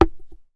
8167 Board Move Or Place Object D 01 1s 0.01 MB